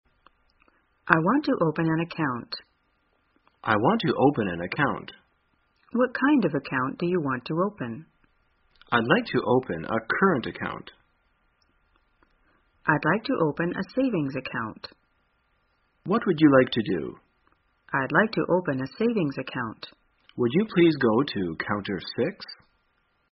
在线英语听力室生活口语天天说 第169期:怎样开账户的听力文件下载,《生活口语天天说》栏目将日常生活中最常用到的口语句型进行收集和重点讲解。真人发音配字幕帮助英语爱好者们练习听力并进行口语跟读。